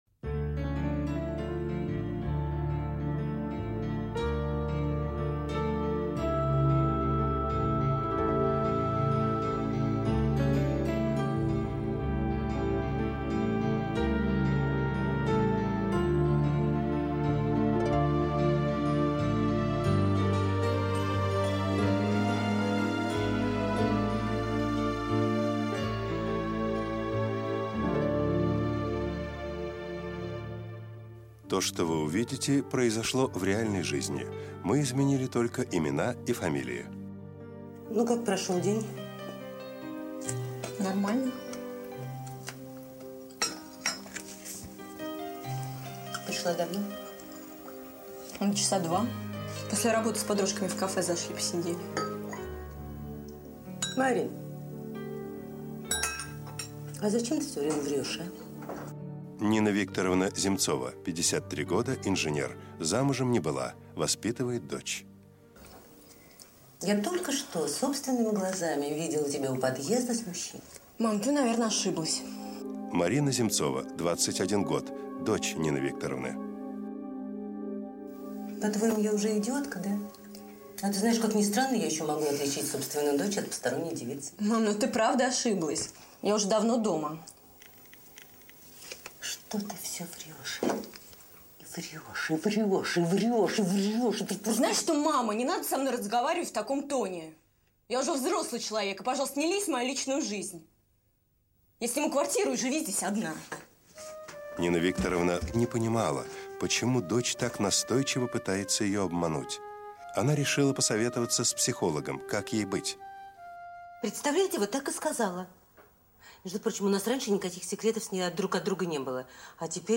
Аудиокнига Ложь во спасение | Библиотека аудиокниг